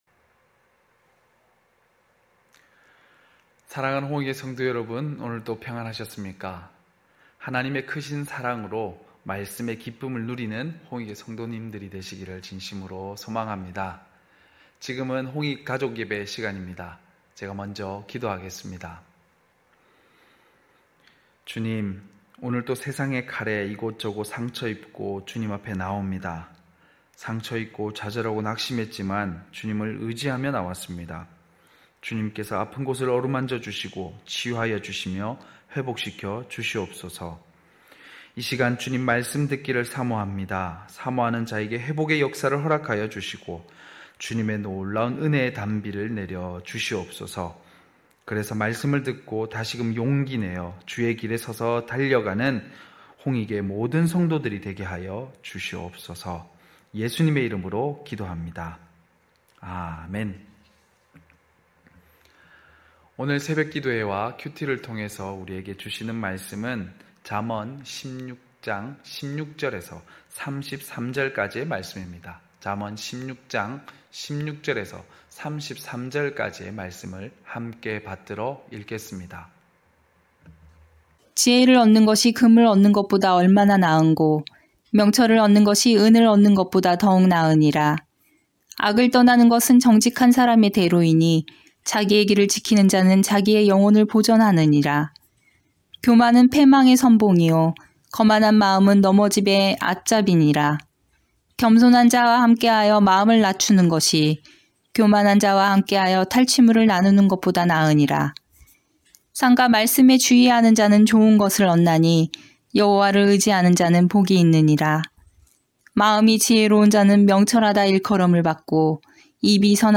9시홍익가족예배(5월31일).mp3